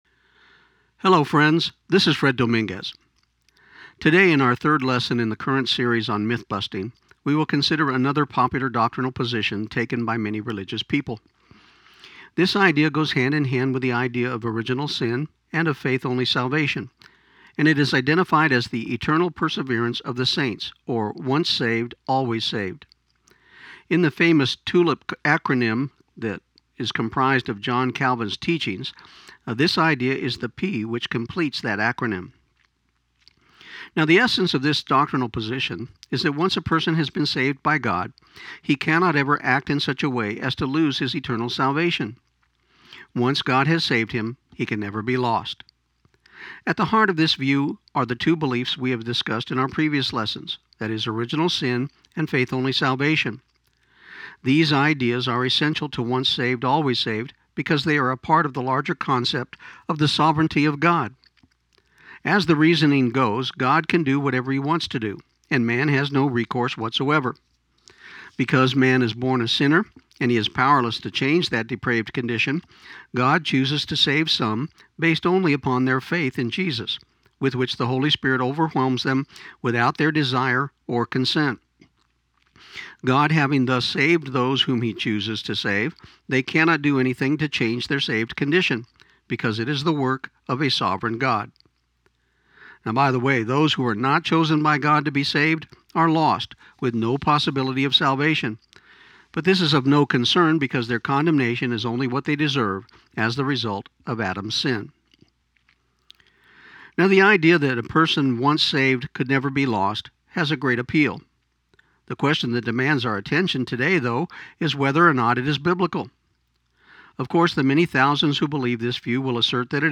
This program aired on KIUN 1400 AM in Pecos, TX on May 15, 2015